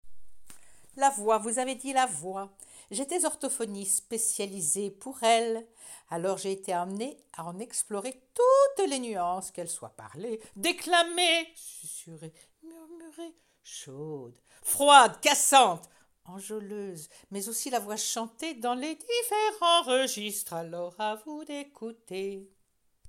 Voix Off notre voix notre empreinte
- Mezzo-soprano